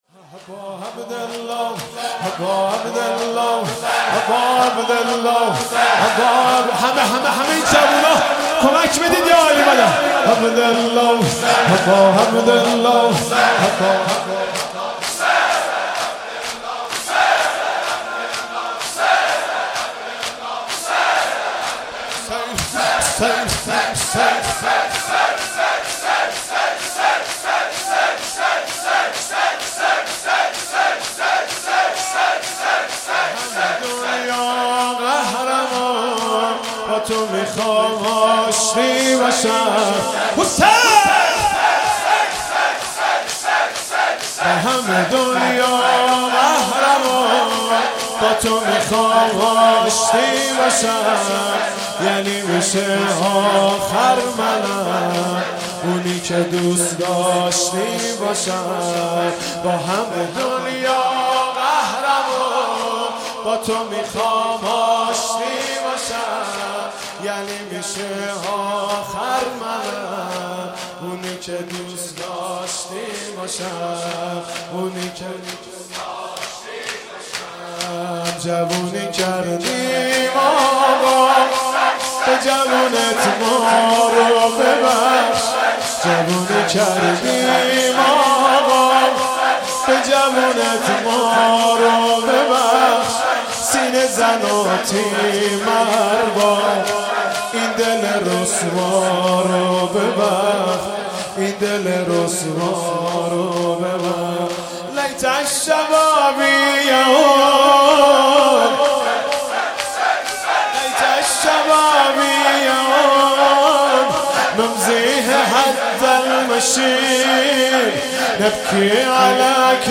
شب 19 رمضان 97 -شور